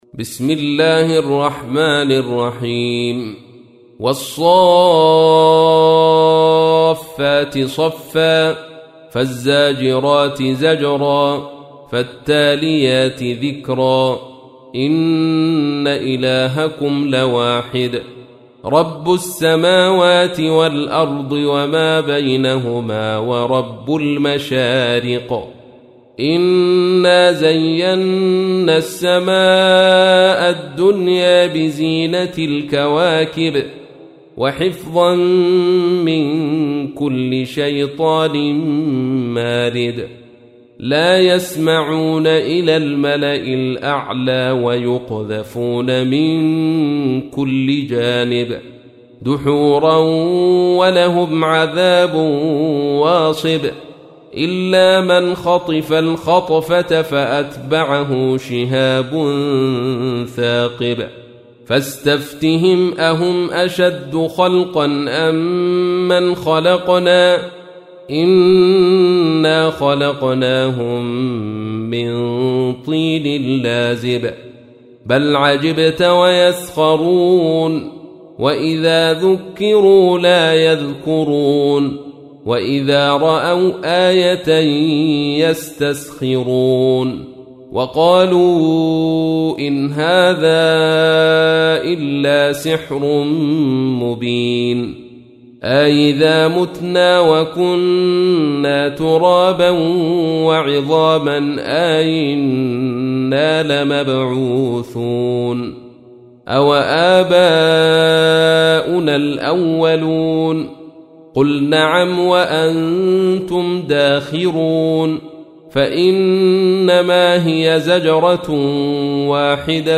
تحميل : 37. سورة الصافات / القارئ عبد الرشيد صوفي / القرآن الكريم / موقع يا حسين